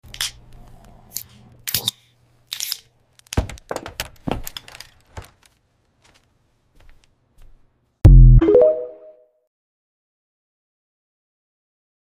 You Just Search Sound Effects And Download. tiktok hahaha sound effect Download Sound Effect Home